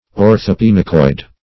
Search Result for " orthopinacoid" : The Collaborative International Dictionary of English v.0.48: Orthopinacoid \Or`tho*pin"a*coid\, n. [Ortho- + pinacoid.]